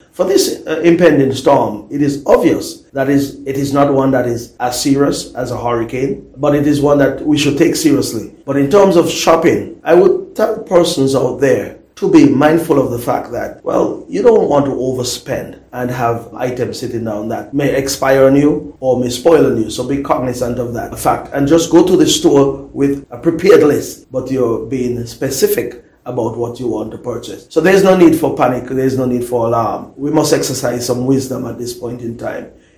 That was the voice of Minister responsible for Disaster on Nevis, Hon. Alexis Jeffers as he is encouraging the public to shop wisely.